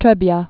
(trĕbyä)